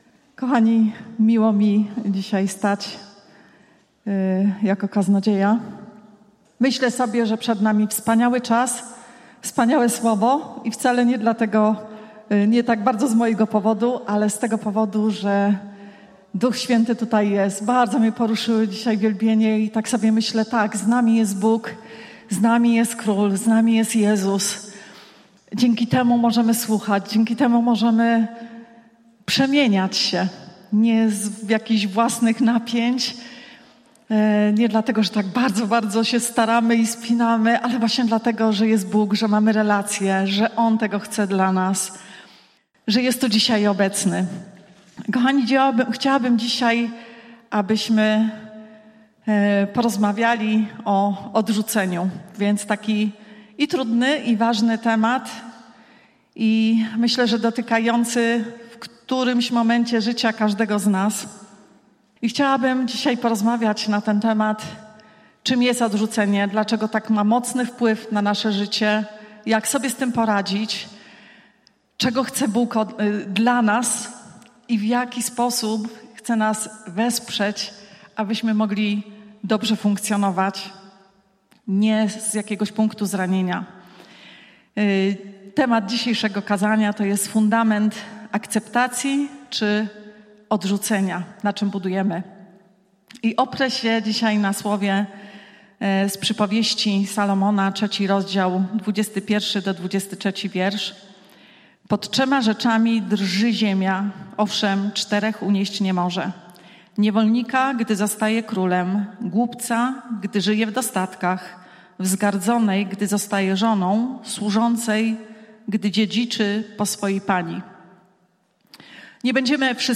– Kościół Zielonoświątkowy – Zbór "Betlejem" w Krakowie | Церква п'ятидесятників в Кракові
Pytania do przemyślenia po kazaniu: